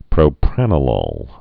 (prō-prănə-lôl, -lōl, -lŏl)